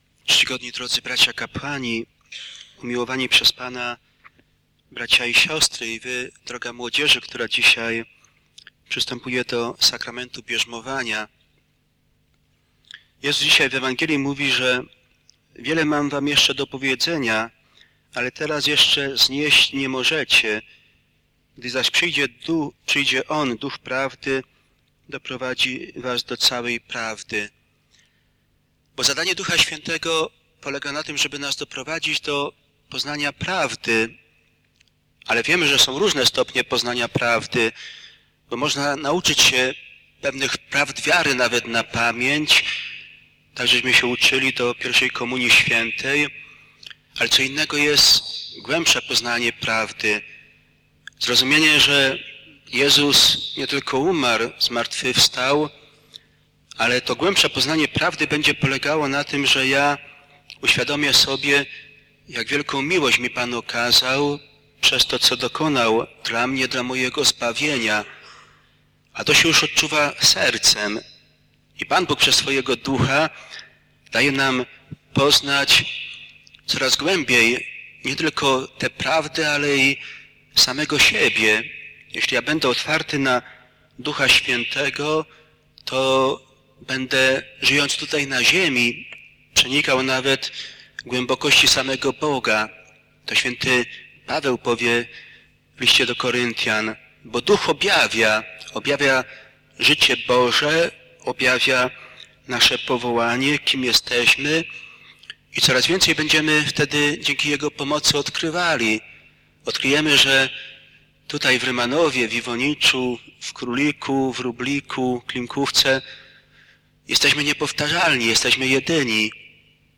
W naszym kościele sakramentu bierzmowania udzielił 28 maja Ksiądz Biskup Stanisław Jamrozek.